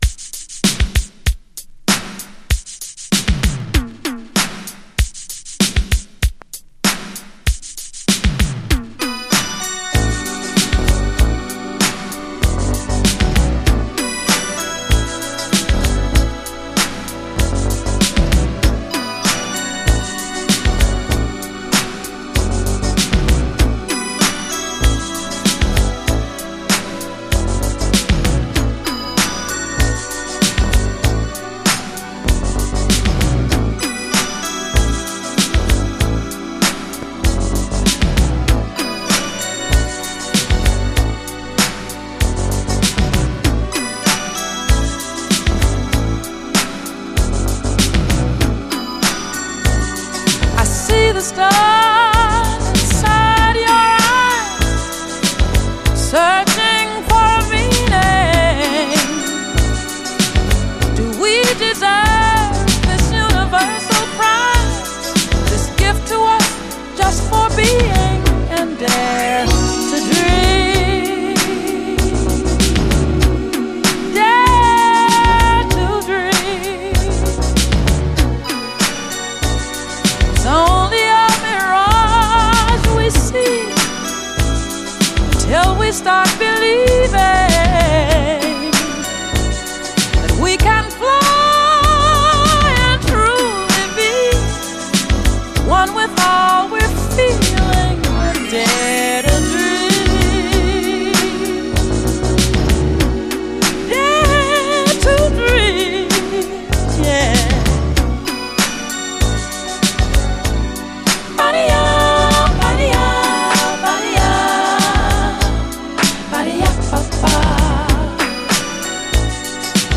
DISCO
最高バレアリック・メロウ・シンセ・ブギー！
ドリーミーなシンセ・サウンドにトロけるめちゃくちゃ最高な一曲！